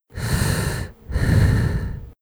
gas_mask_light_breath2.wav